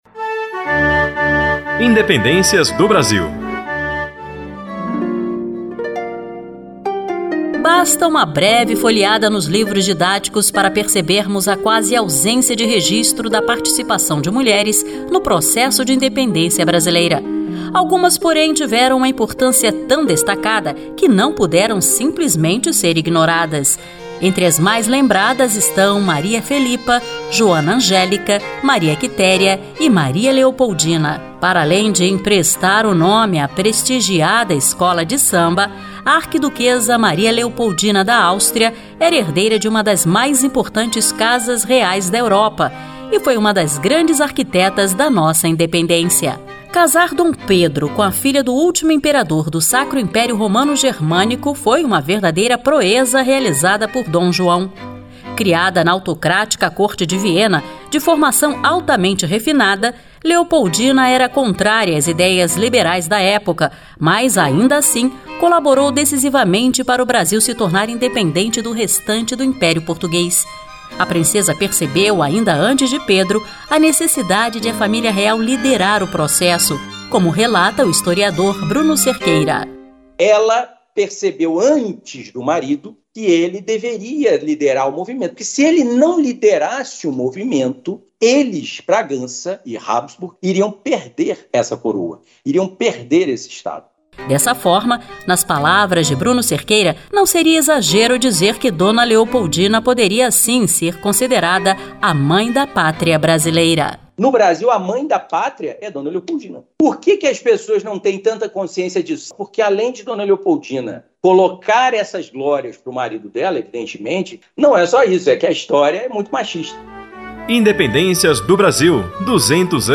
A história da independência brasileira é fruto de uma série de eventos ocorridos entre o final do século XVIII e o início do século XIX, que moldaram a modernidade até os dias atuais. Para celebrar os 200 anos da Independência do Brasil, a Rádio Senado ouviu historiadores e produziu uma série de seis programetes que contam como o país conquistou sua autonomia em 1822.